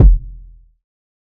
TC2 Kicks17.wav